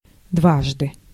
Ääntäminen
IPA : /twaɪs/ US : IPA : [twaɪs]